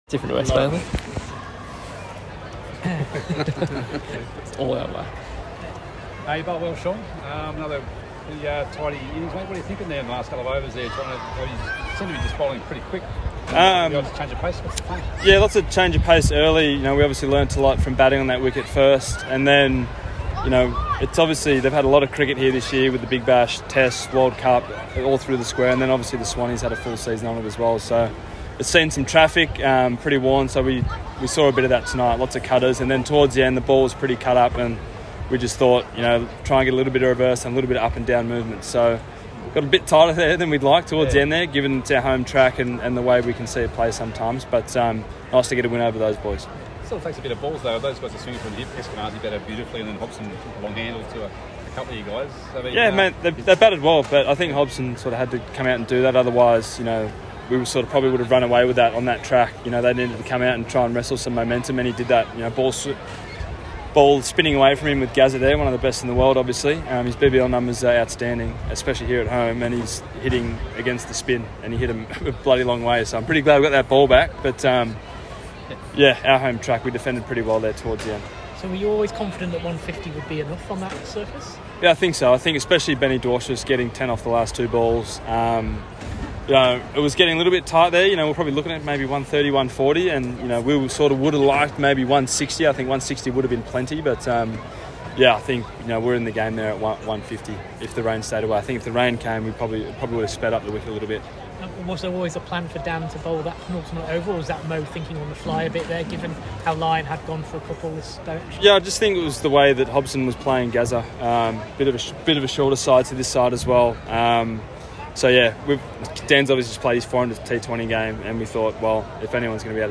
Sean Abbott POTM (3-18) speaking post Sixers win over the Scorchers at the SCG.